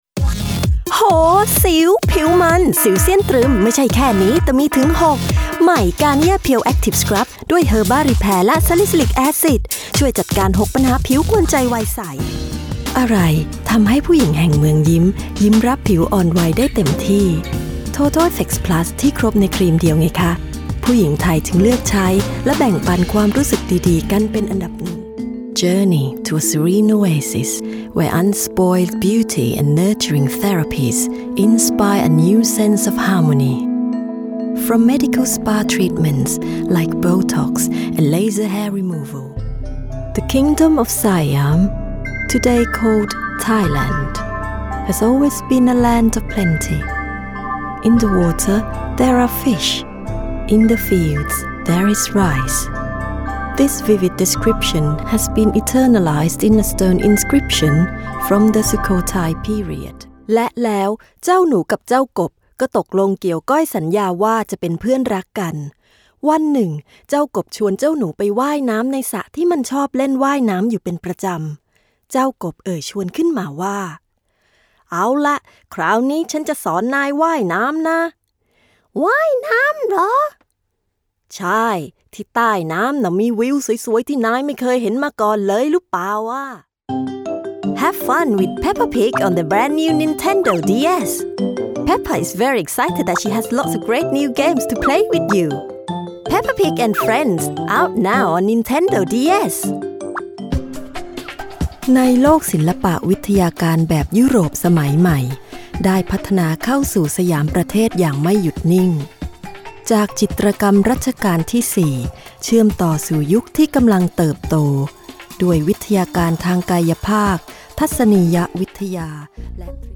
Thai-British Actress | Voice-Over Artist | Language Director
Her voice is warm with a smooth tone as well as being fun, fresh and adaptable with a diverse range of character voices.
Thai & English voice reel samples